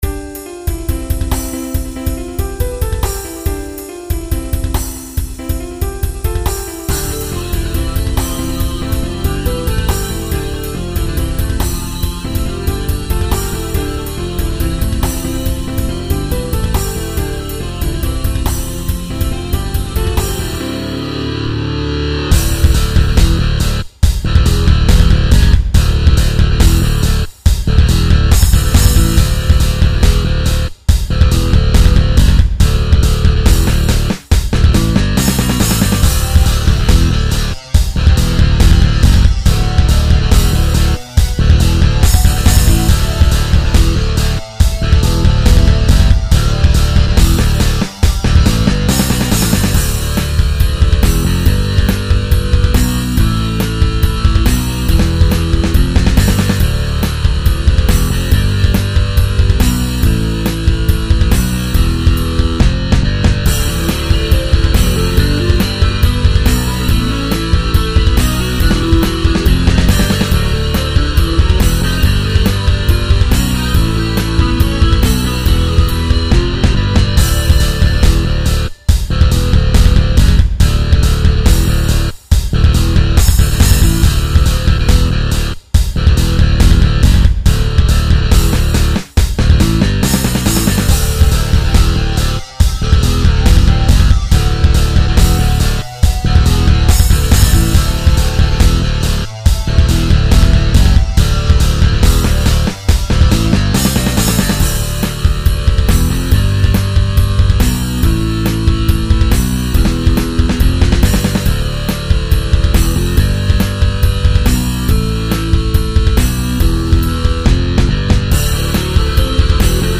Rock
No vocals yet... but I gots lyrics!
Dropped D Tuning
Boss Reverb
Dunlop Wah
Digitech MultiChorus
Digitech Digidelay
Digitech DF-7 Distortion